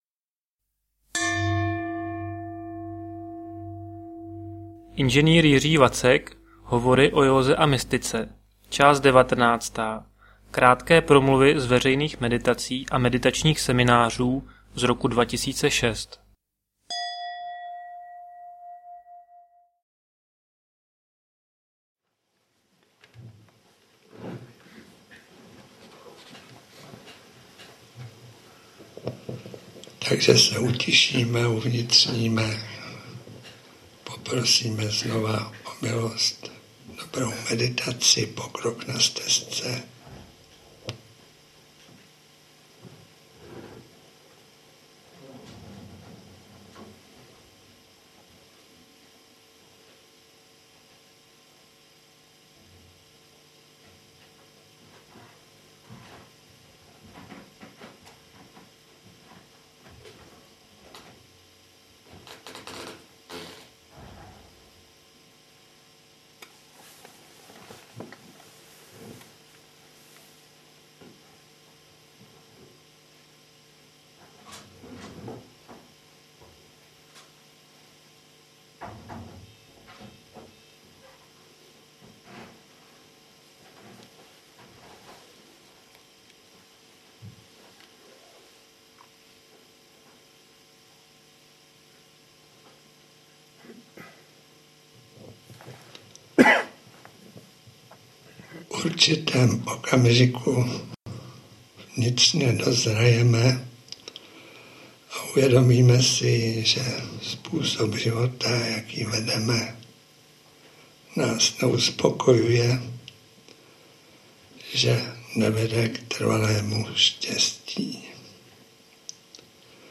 Hovory o józe a mystice 19.: Promluvy